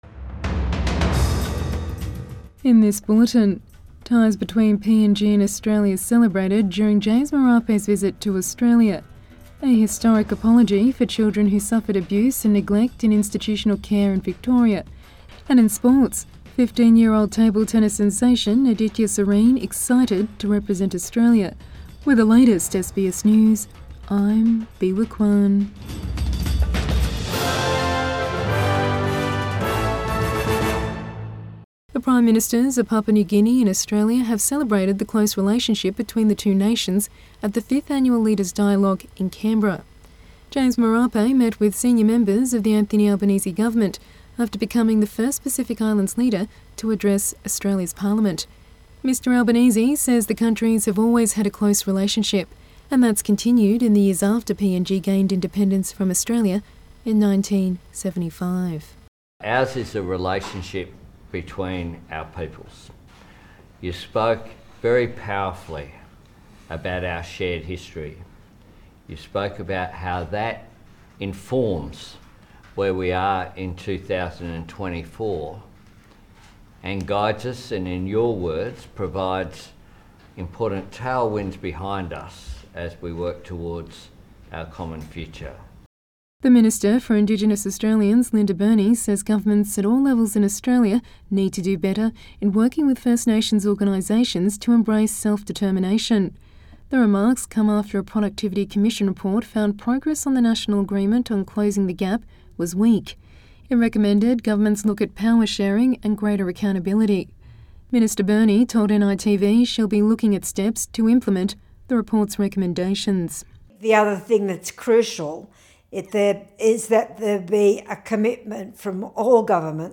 Evening News Bulletin 8 February 2024